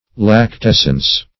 Lactescence \Lac*tes"cence\, n. [Cf. F. lactescence.]